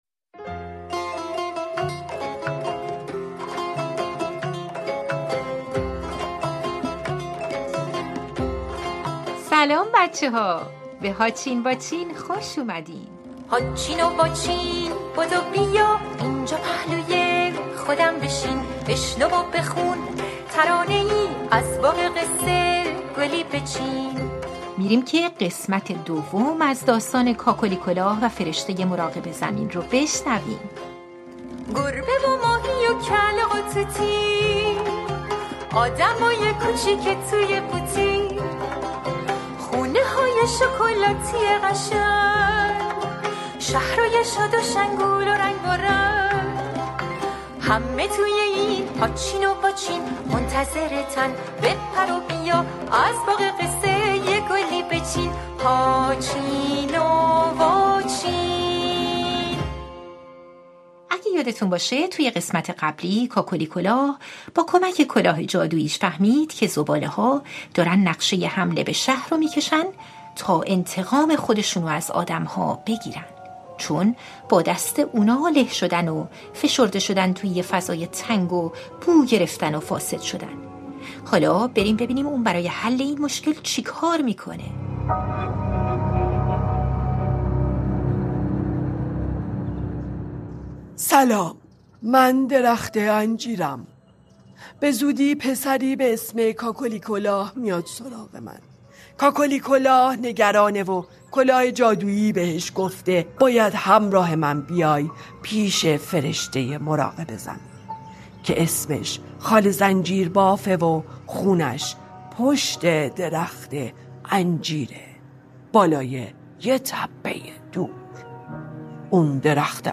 سه‌تار